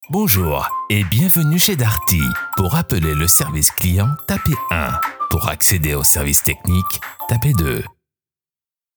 IVR
Micro SE Electronics und Beats Mixr